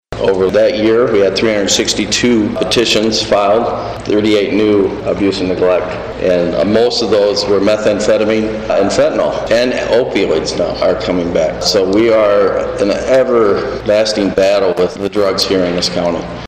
During their meeting Tuesday, the St. Joseph County Commission heard the Juvenile Division’s annual report for 2024. Judge Kevin Kane shares the case load for 2024 in the court.